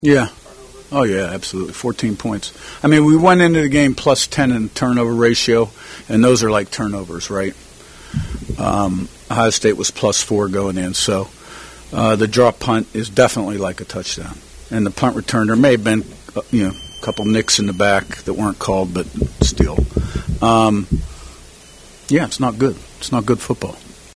Indiana Hoosiers Postgame Press Conference with head coach Curt Cignetti after loss at #2 Ohio State, 38-15